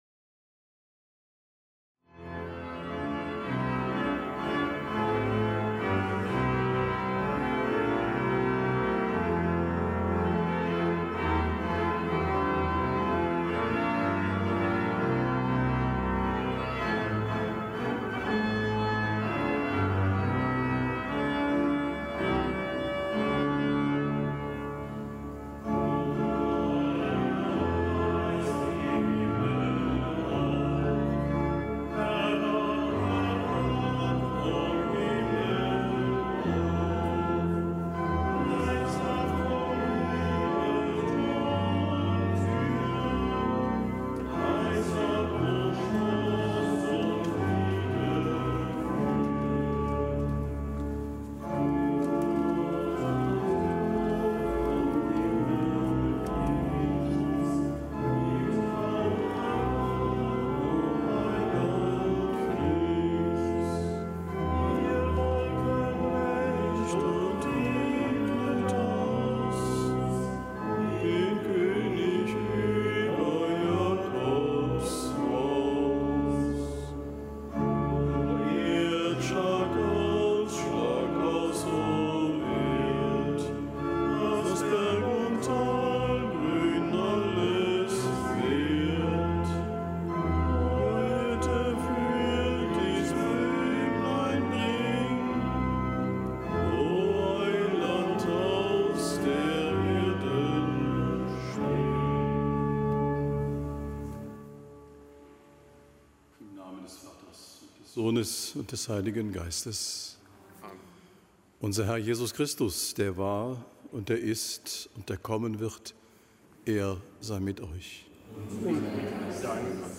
Kapitelsmesse am Mittwoch der zweiten Adventswoche
Kapitelsmesse aus dem Kölner Dom am Mittwoch der zweiten Adventswoche, nichtgebotener Gedenktag des Heiligen Damasus I., Papst.